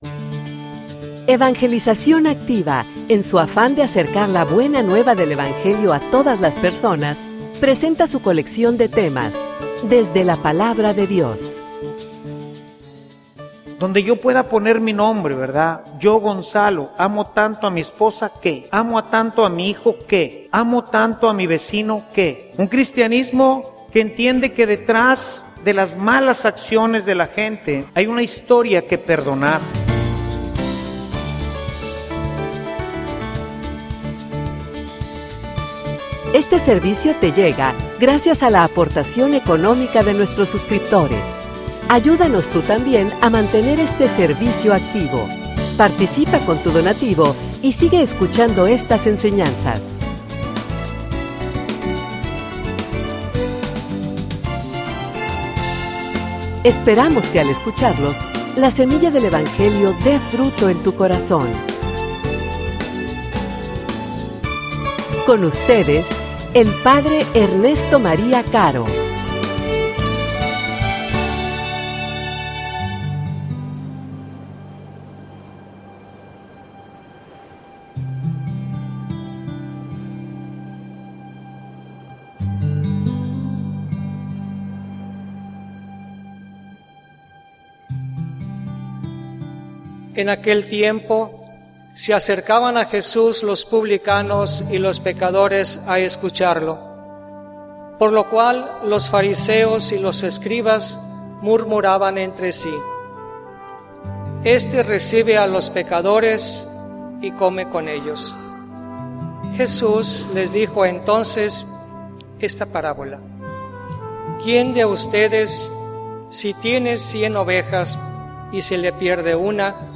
homilia_Misericordiosos_como_el_Padre.mp3